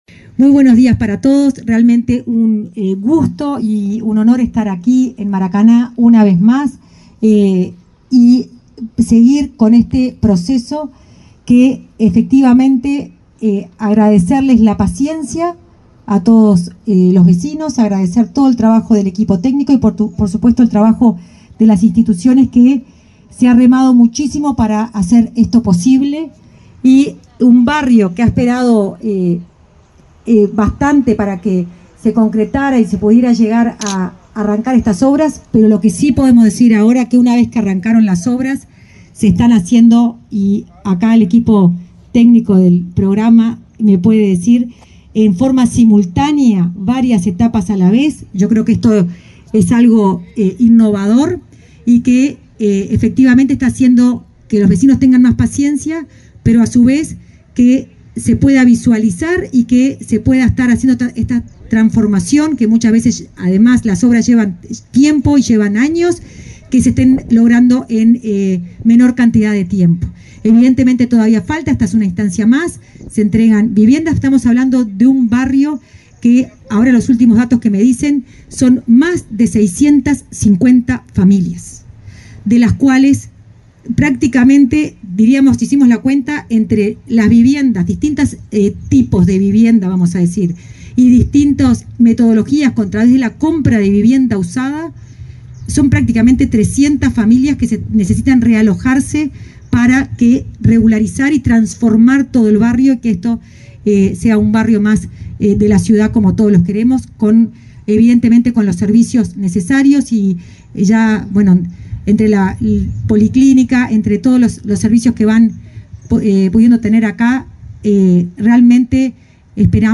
Palabras de autoridades en inauguración de vivienda en Montevideo
Palabras de autoridades en inauguración de vivienda en Montevideo 17/12/2024 Compartir Facebook X Copiar enlace WhatsApp LinkedIn La directora de Integración Social y Urbana del Ministerio de Vivienda, Florencia Arbeleche, y el titular de la cartera, Raúl Lozano, participaron en la inauguración de viviendas en el barrio Maracaná, en Montevideo.